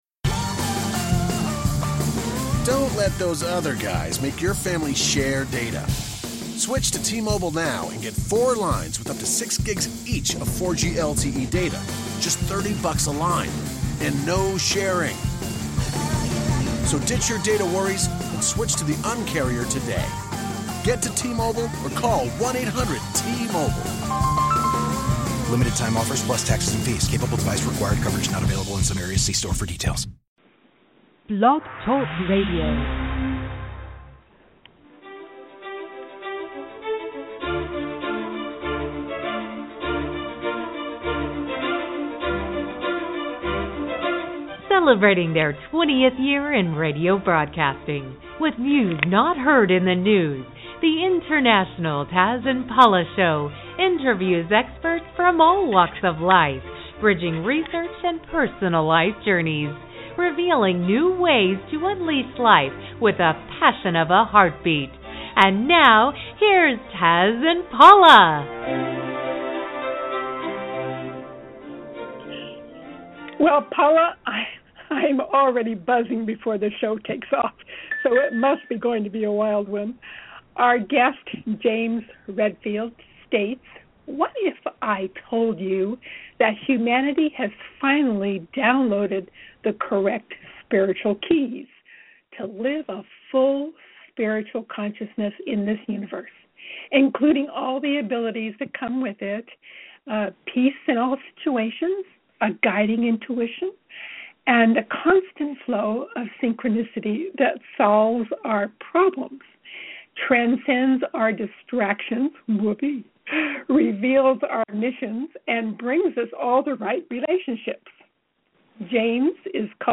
Guest, James Redfield